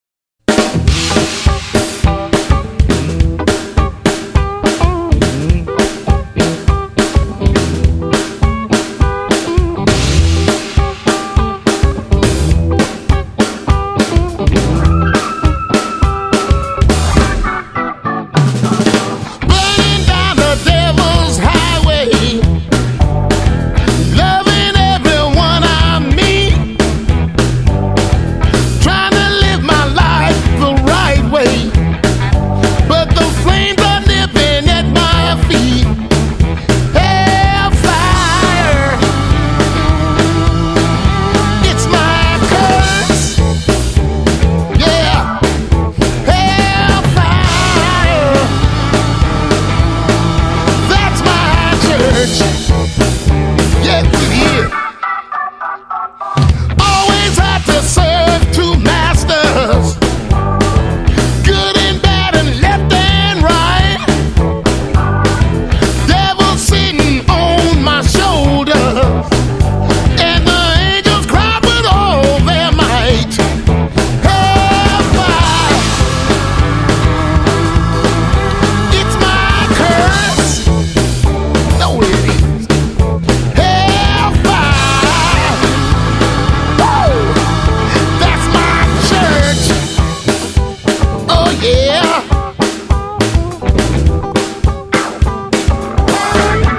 藍調音樂